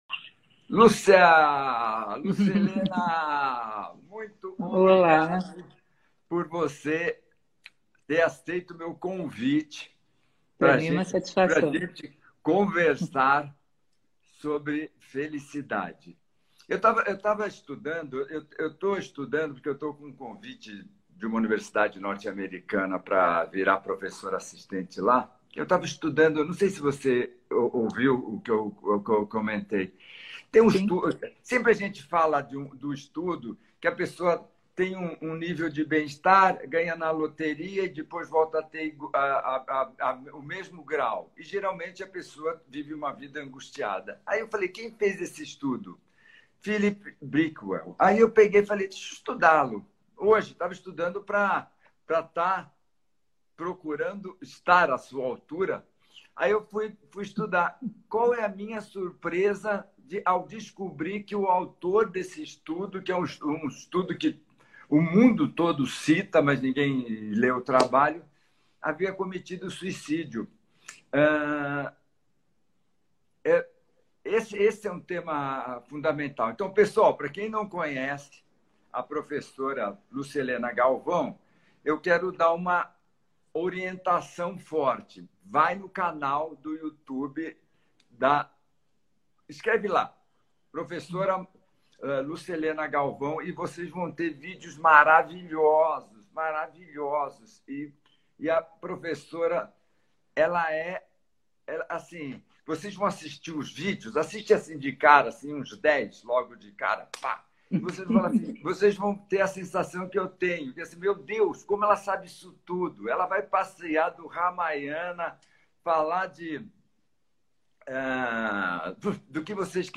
#327 - Felicidade, live com Roberto Shinyashiki e Lúcia Helena Galvão